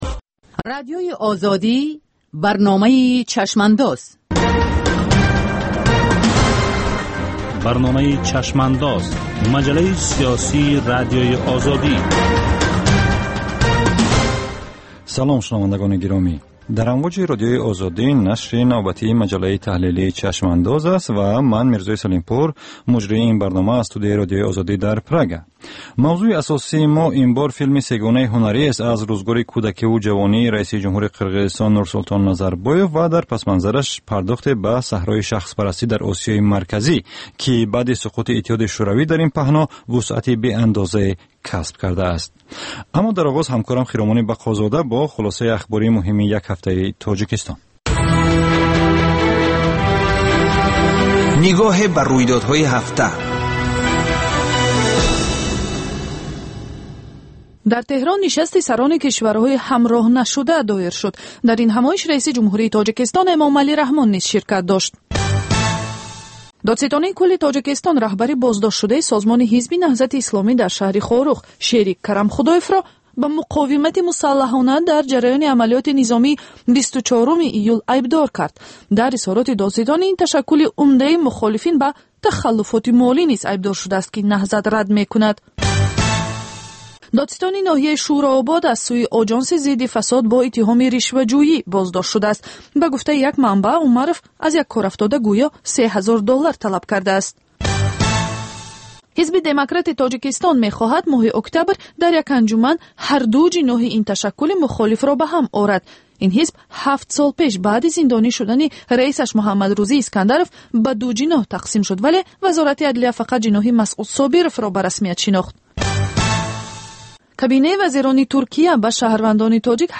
Ҷусторе дар рӯйдодҳои сиёсии ҷаҳон, минтақа ва Тоҷикистон дар як ҳафтаи гузашта. Мусоҳиба бо таҳлилгарони умури сиёсӣ.